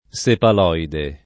[ S epal 0 ide ]